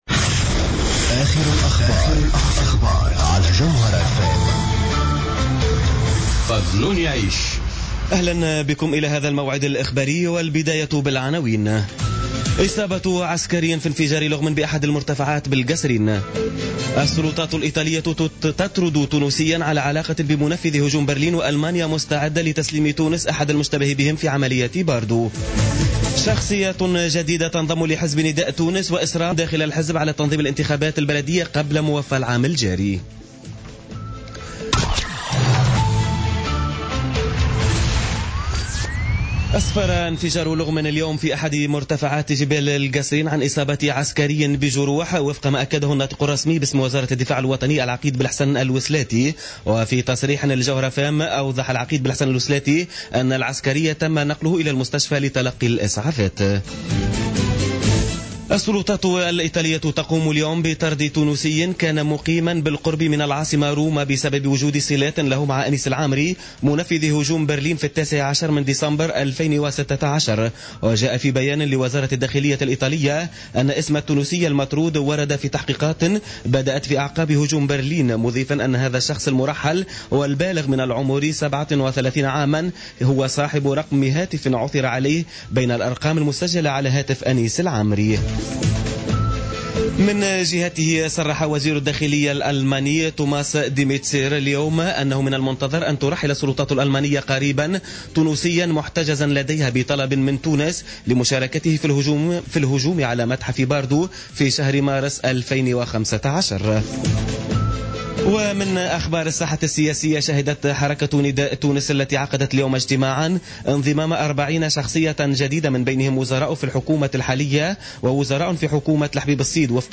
نشرة أخبار السابعة مساء ليوم الأحد 12 مارس 2017